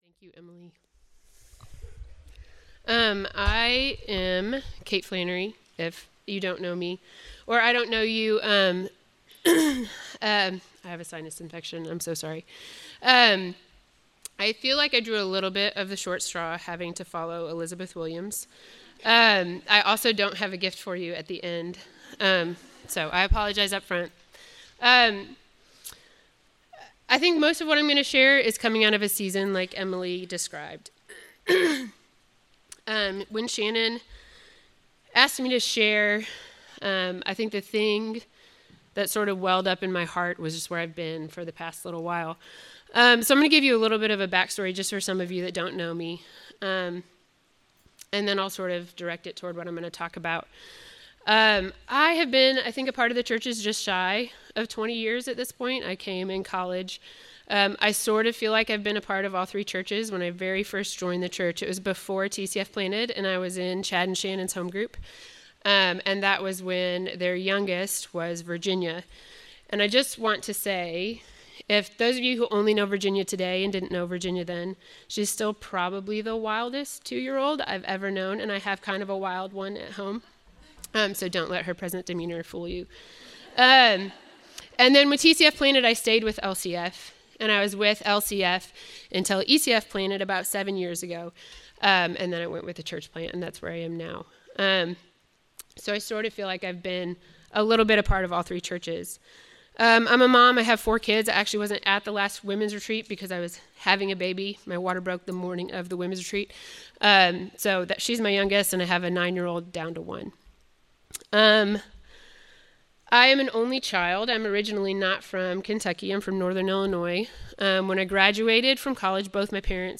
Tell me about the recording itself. women_retreat_2025_session2.mp3